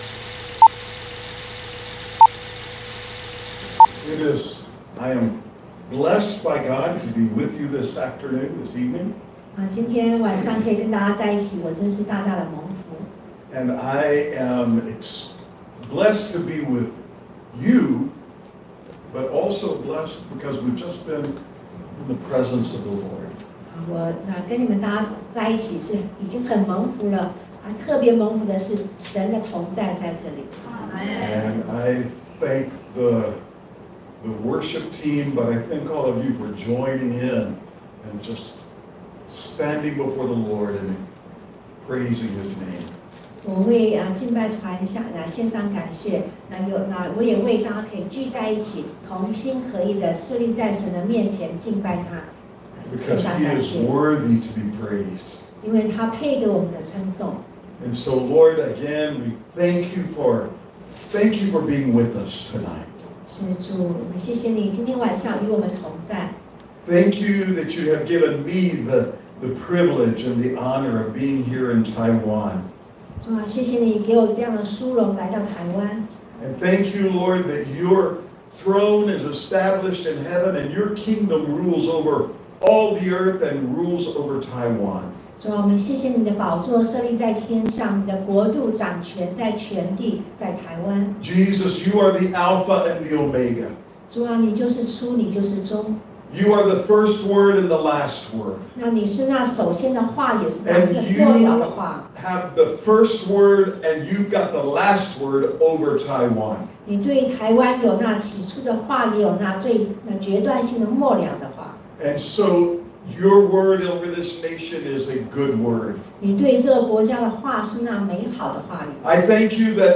地點：花蓮CPE領袖學院